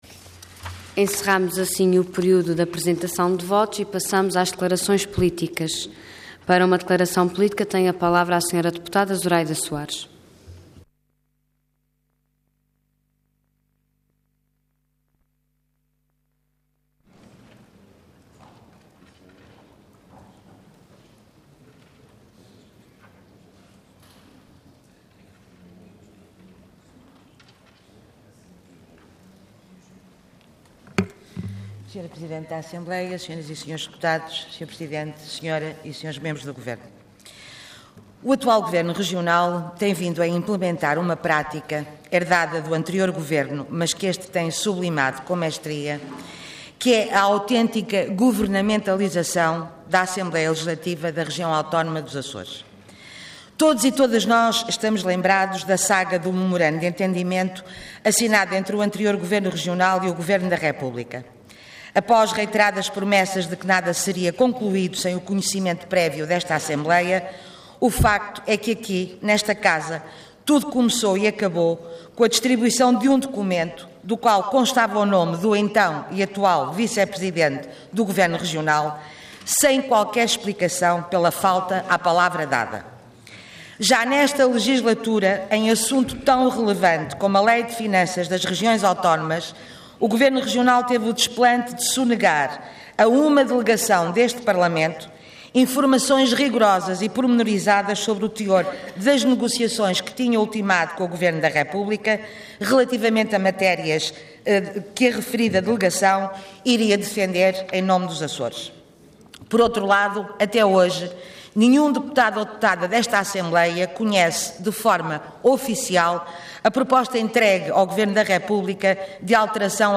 Parlamento online - Intervenção durante o período de tratamento de assuntos políticos.
Intervenção Declaração Política Orador Zuraida Soares Cargo Deputada Entidade BE